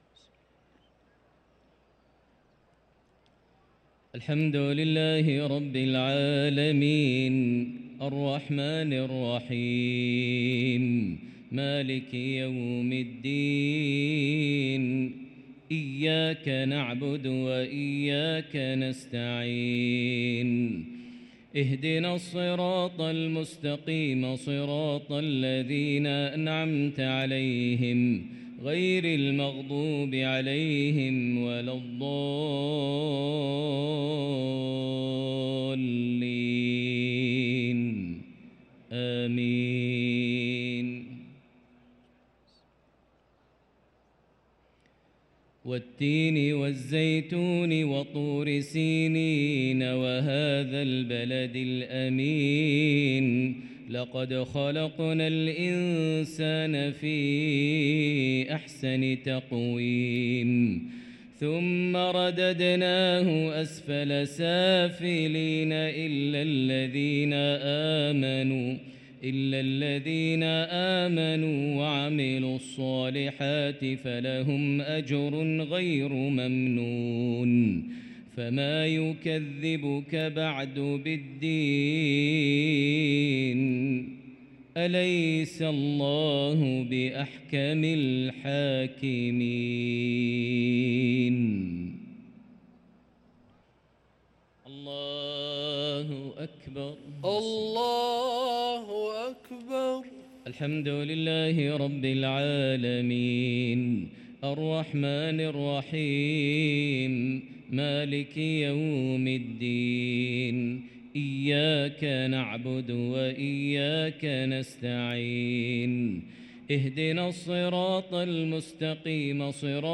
صلاة المغرب للقارئ ماهر المعيقلي 5 ربيع الأول 1445 هـ
تِلَاوَات الْحَرَمَيْن .